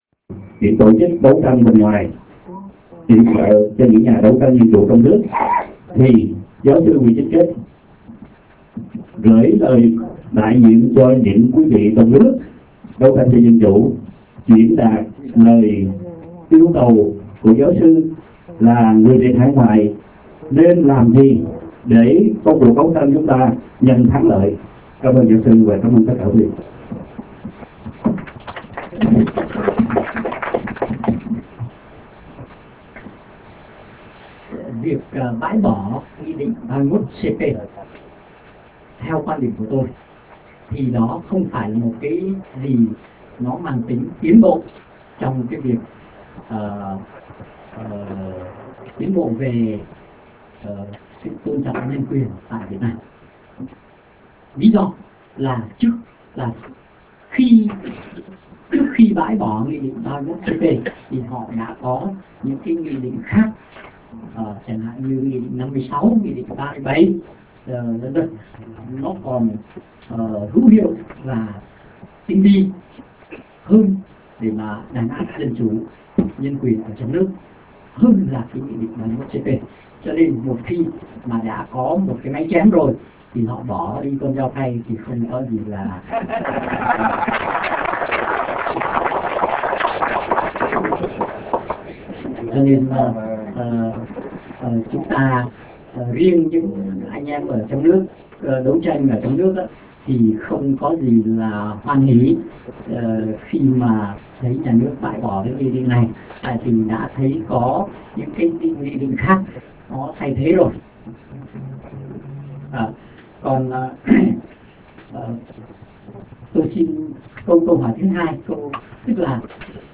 Buổi họp b�o